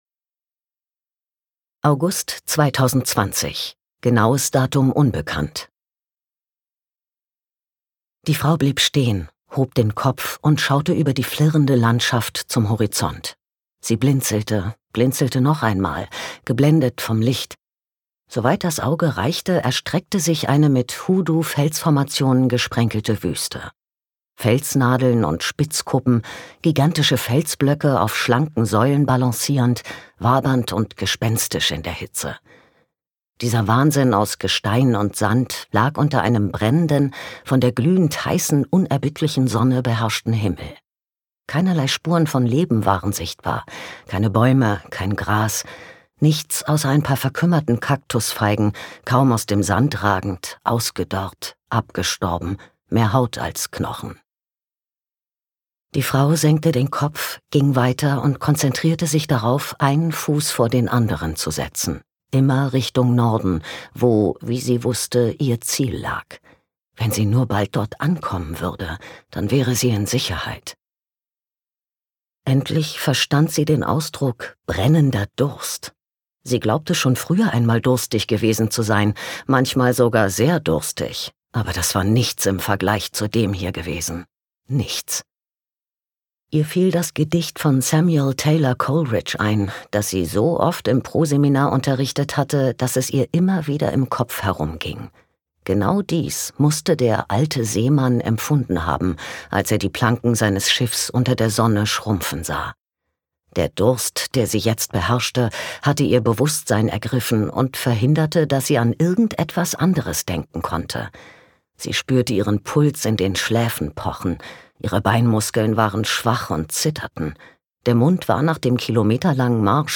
Old Bones – Der Fluch der Wüste - Douglas Preston, Lincoln Child | argon hörbuch
Gekürzt Autorisierte, d.h. von Autor:innen und / oder Verlagen freigegebene, bearbeitete Fassung.